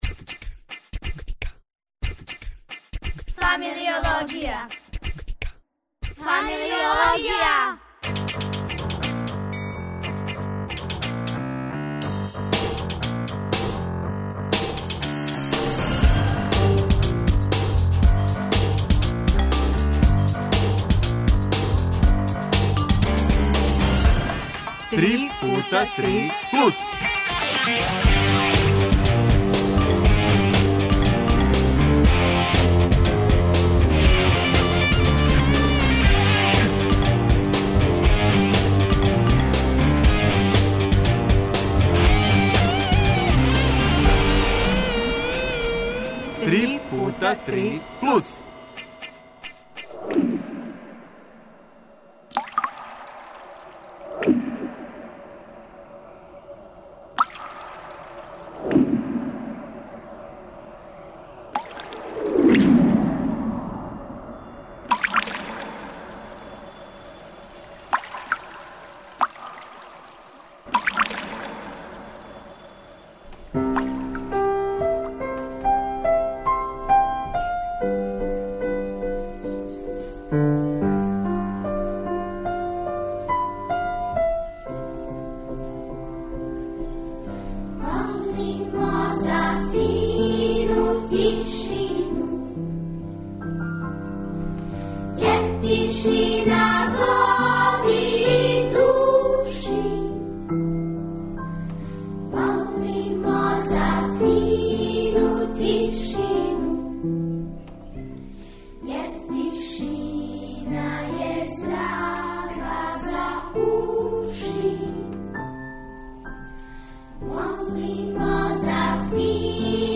Гости су млади глумци из "Маске" који, пре свега, трагају: за сопствени идентитетом, добрим текстовима, улогама...